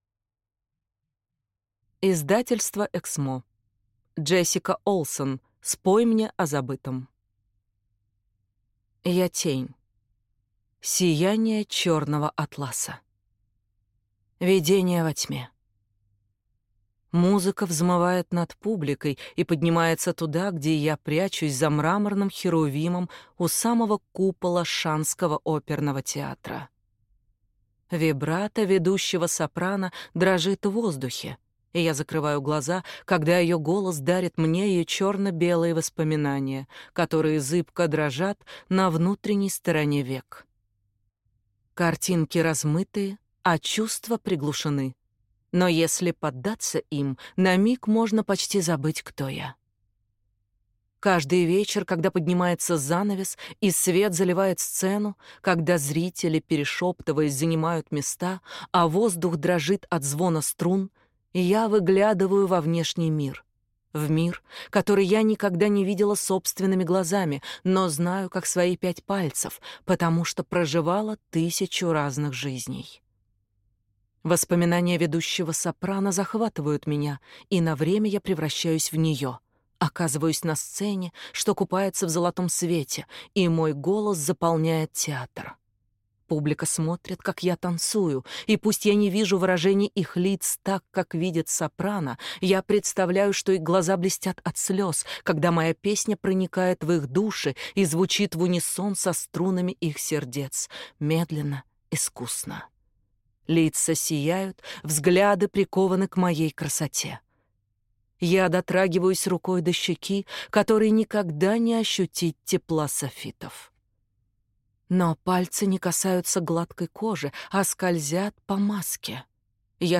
Аудиокнига Спой мне о забытом | Библиотека аудиокниг